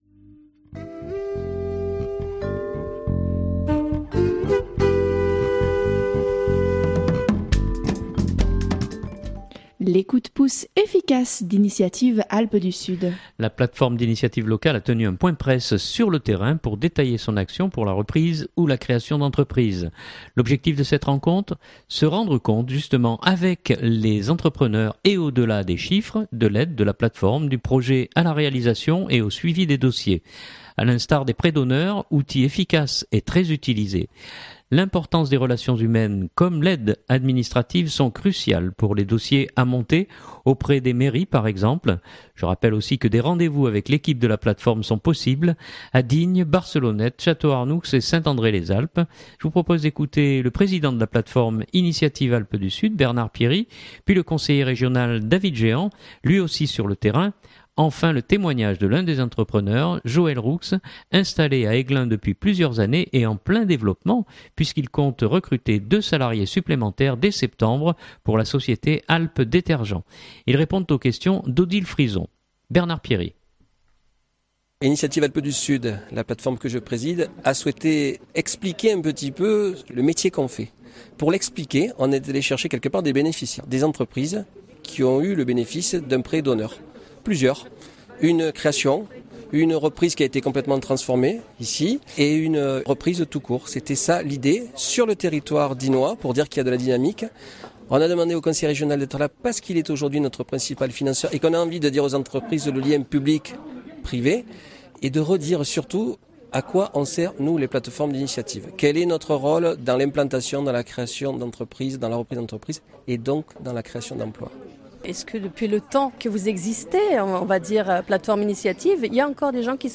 La plateforme d’initiative locale a tenu un point presse sur le terrain pour détailler son action pour la reprise ou la création d’entreprise. L’objectif de cette rencontre : se rendre compte avec les entrepreneurs et au-delà des chiffres, de l’aide de la plateforme du projet à la réalisation et au suivi des dossiers.